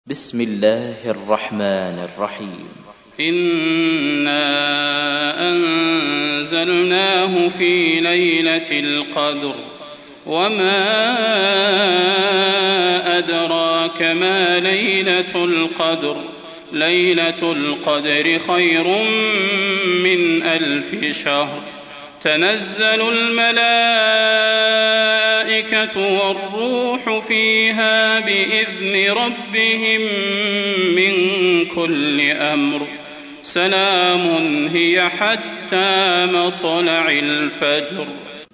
تحميل : 97. سورة القدر / القارئ صلاح البدير / القرآن الكريم / موقع يا حسين